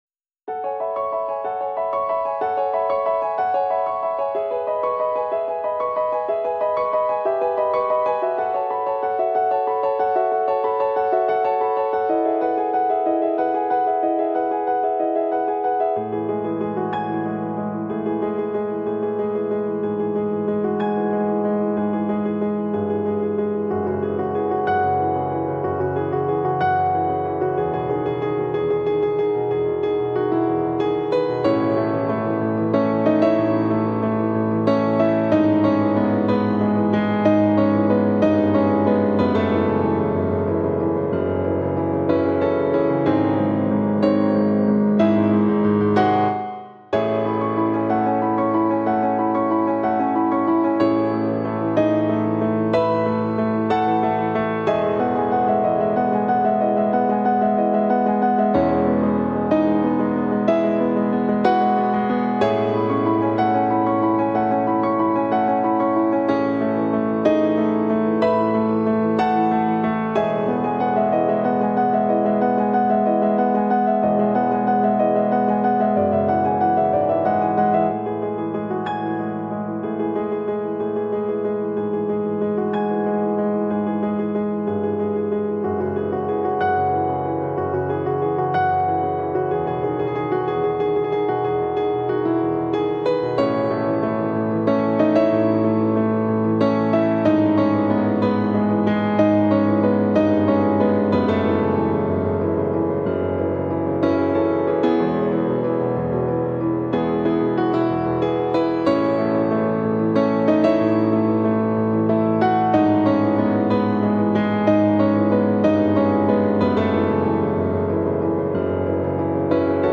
asmr study [ 2 7 2025 ] sound effects free download